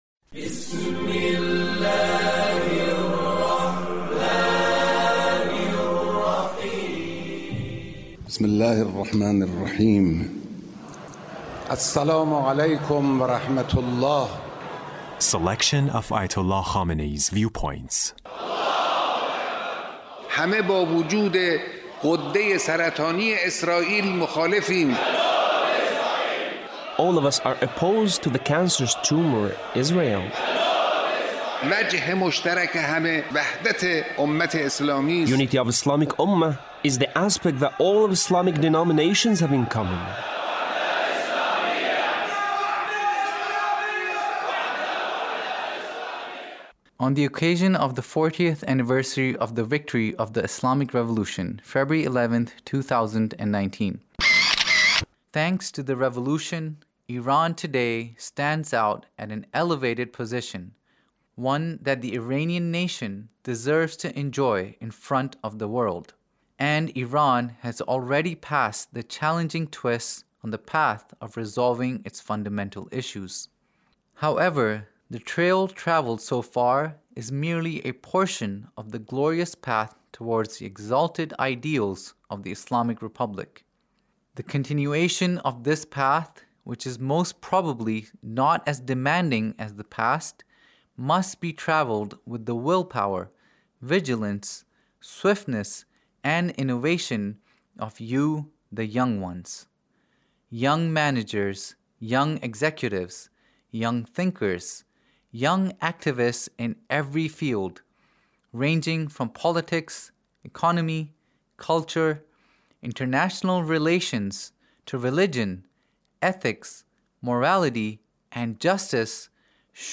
Leader's Speech (1884)